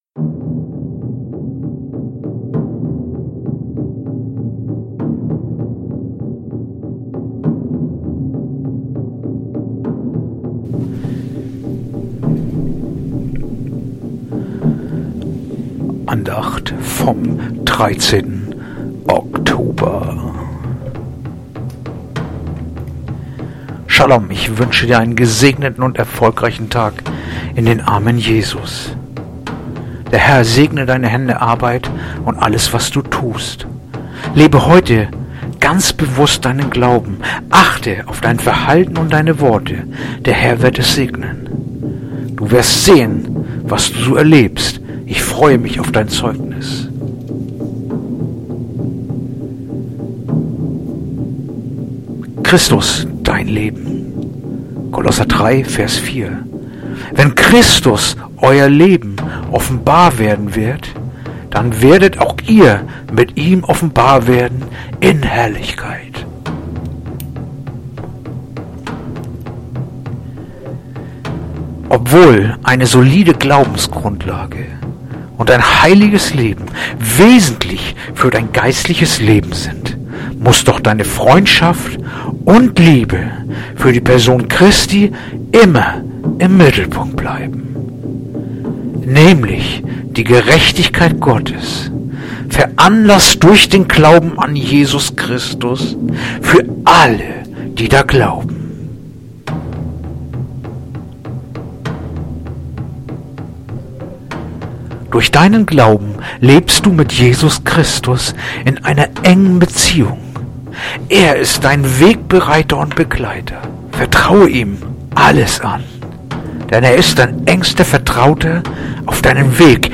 Andacht-vom-13-Oktober-Kolosser-3-4.mp3